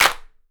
Southside Clapz (22).wav